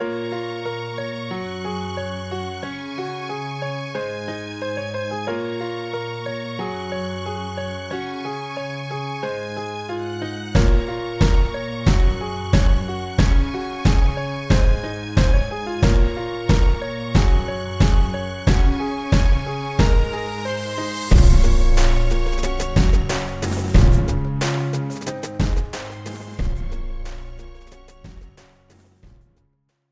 This is an instrumental backing track cover.
• Key – B♭m
• Without Backing Vocals
• No Fade